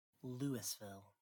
Pronunciation
All three are generally considered acceptable; the Louisville Visitor Center says that only the rare /ˈlɪsvɪl/
LOO-iss-vil is completely unacceptable (though it is the correct pronunciation for the name of the much smaller Louisville, Colorado).